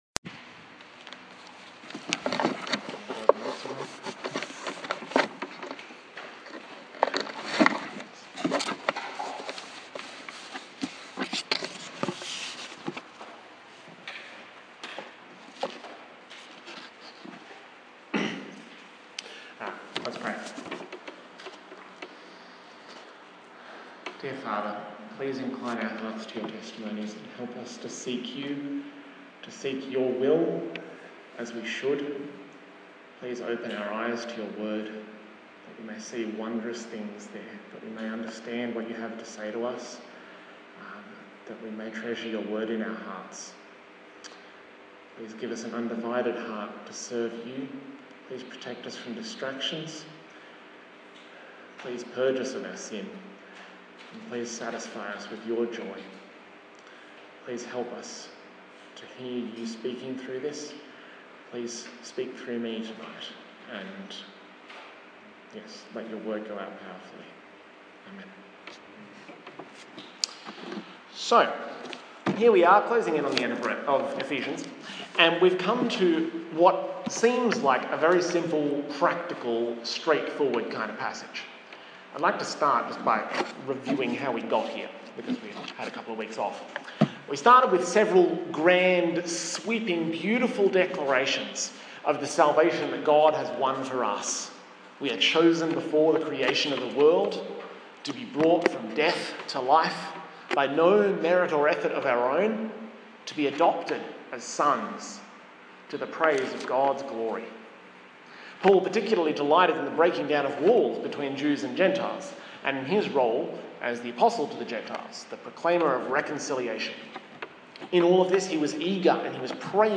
The eleventh in the sermon series on Ephesians